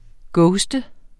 Udtale [ ˈgɔwsdə ]